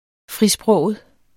frisproget adjektiv Bøjning -, frisprogede Udtale [ ˈfʁiˌsbʁɔˀwəð ] Betydninger det at have en fri sprogbrug og sige sin mening uden at lade sig begrænse eller censurere af etikette, eventuelle sanktioner osv.